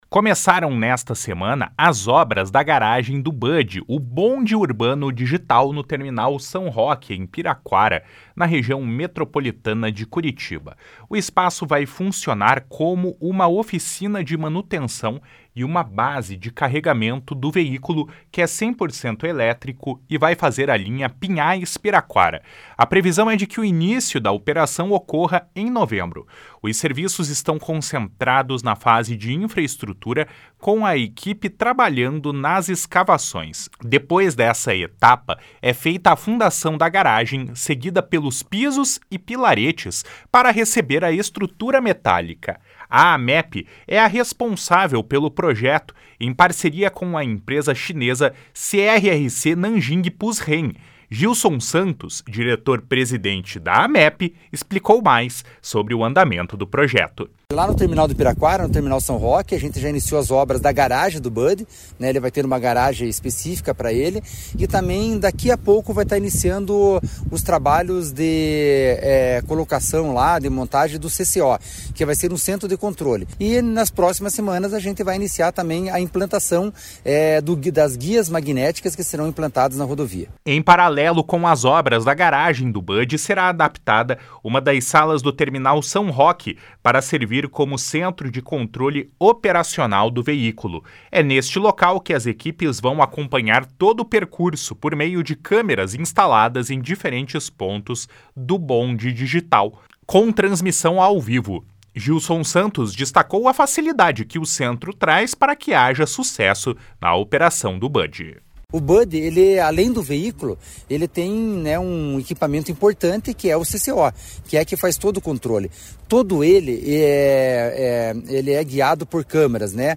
Gilson Santos, diretor-presidente da Amep, explicou mais sobre o andamento do projeto. // SONORA GILSON SANTOS //